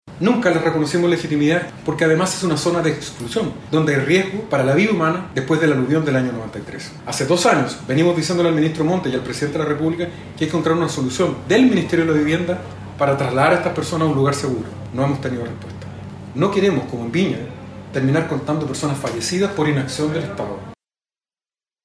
Consultado por La Radio, el alcalde aseguró que en la zona efectivamente existe un riesgo de aluvión luego de los desastres ocurridos en el pasado, por lo que emplazó al ministro de Vivienda, Carlos Montes, a actuar, y así evitar tragedias por inactividad del Estado.